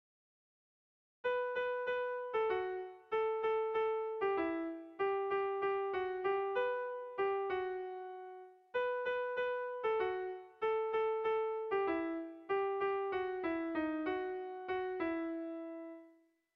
Haurrentzakoa
Ereño < Busturialdea < Bizkaia < Euskal Herria
Lauko handia (hg) / Bi puntuko handia (ip)
A1A2